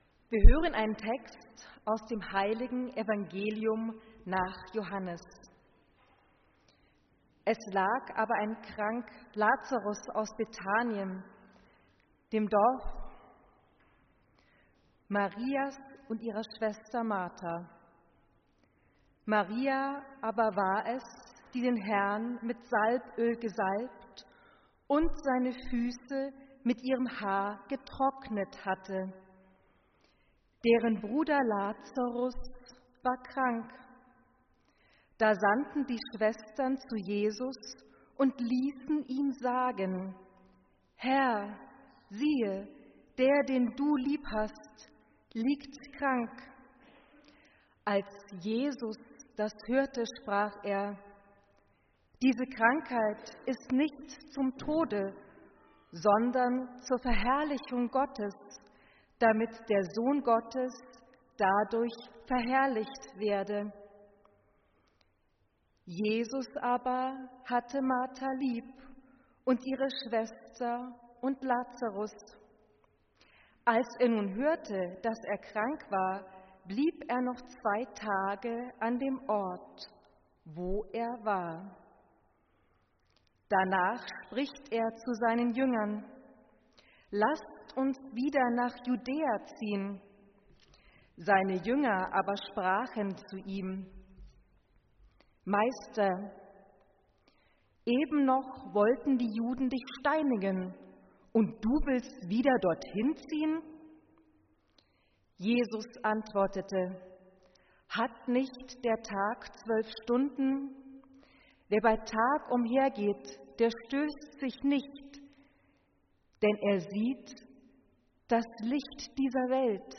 Predigt
im Rahmen der Gebetswoche für die Einheit der Christen